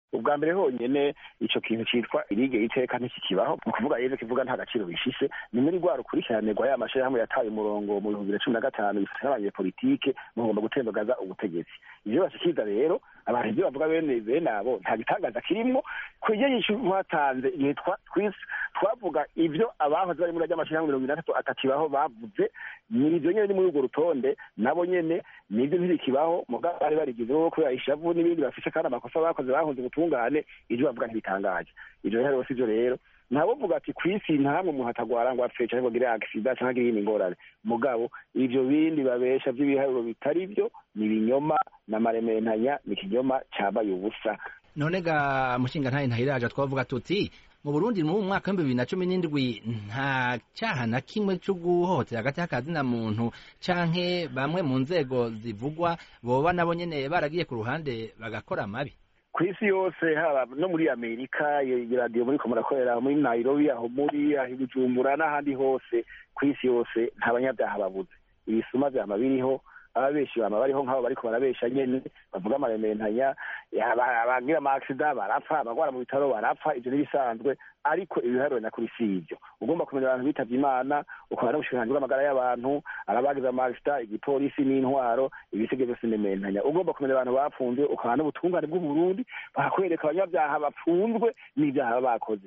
kuri terefone